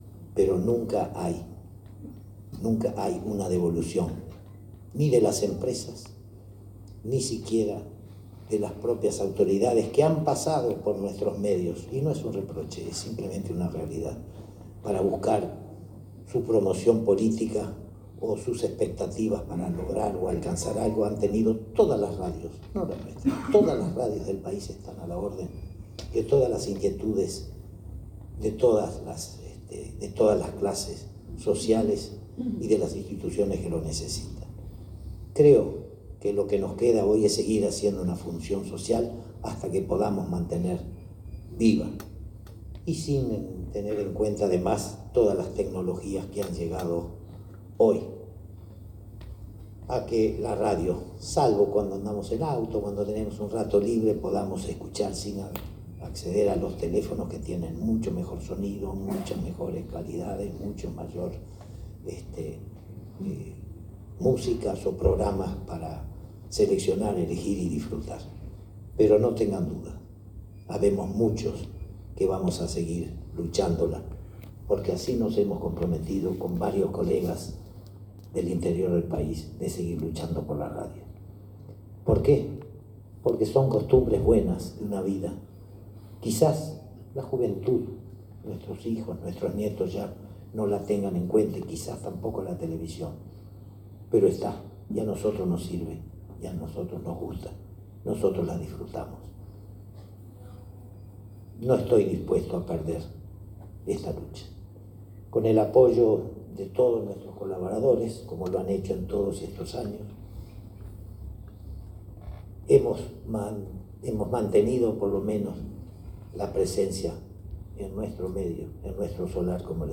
en el acto con el que se celebró el centenario de la emisora